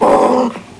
Growl Sound Effect
Download a high-quality growl sound effect.
growl.mp3